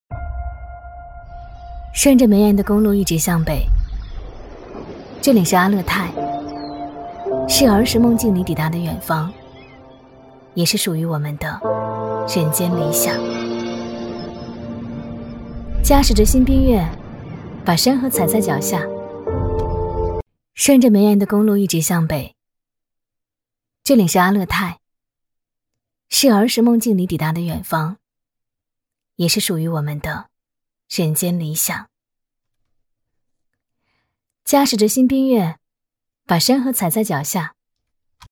女B32-TVC -素人风
女B32-大气质感 低沉走心
女B32-TVC -素人风.mp3